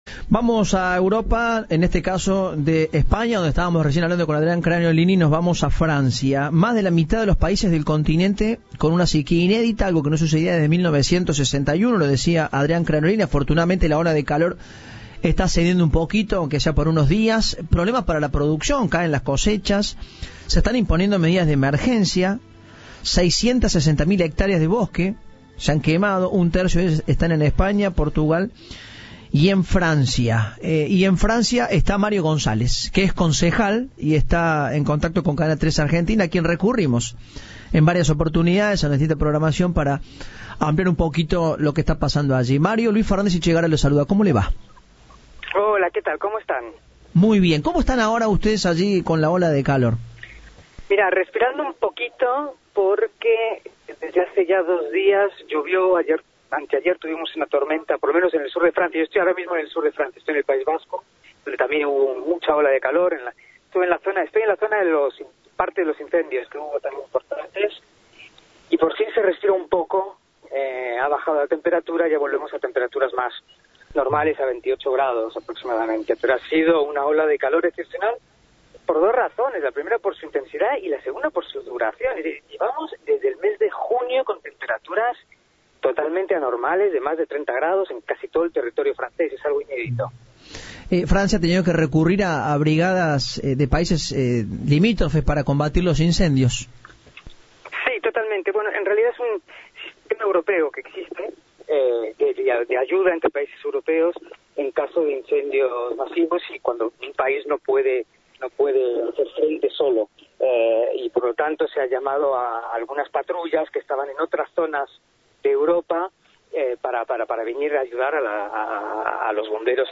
En diálogo con Cadena 3, Mario González, concejal en Francia, dijo que el cuerpo de bomberos “lleva semanas agotado y ha pedido ayuda para controlar estos incendios”.